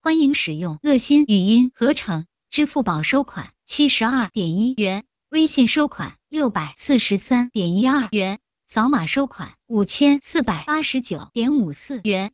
esp-tts / samples
xiaoxin_speed1.wav